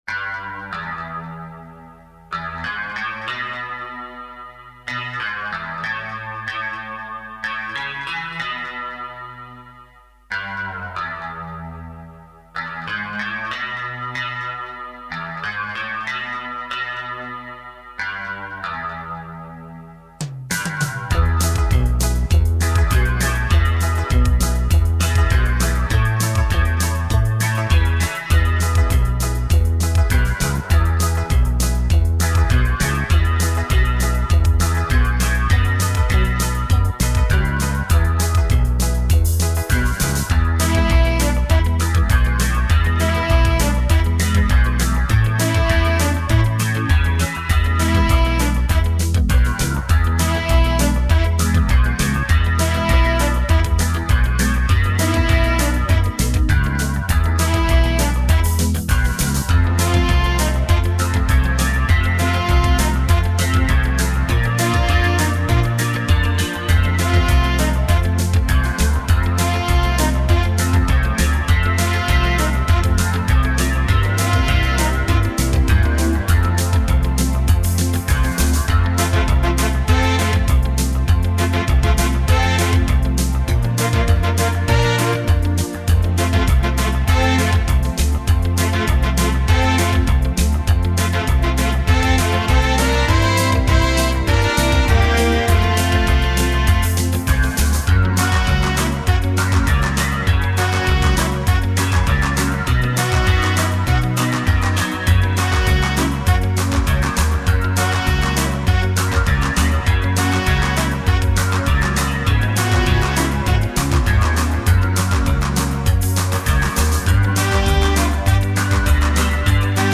У группы Dave Clark Five  похожий хрипящий саксофончик,   но скорость воспроизведения вроде должно быть повыше.....,,????
Скорость повысить необходимо ,  Вещь очень известная,  половина форумчан ее слышали, и 5 % знают ее название, НО НЕ Я..